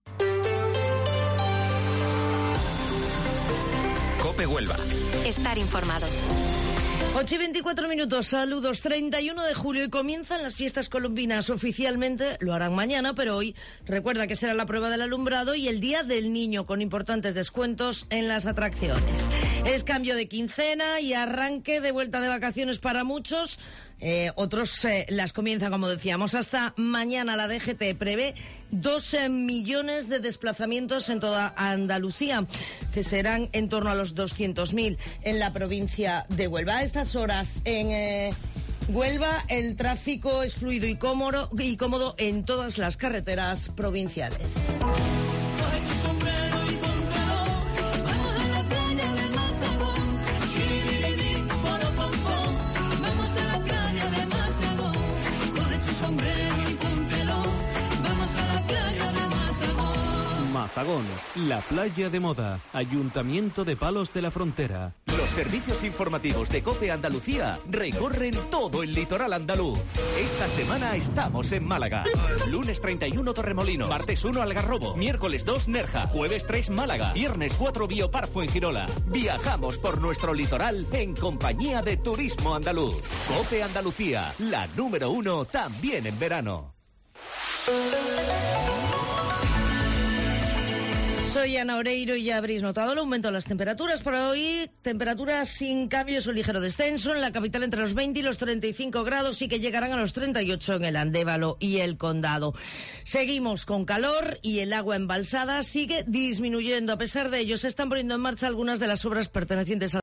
Informativo Matinal Herrera en COPE 31 de julio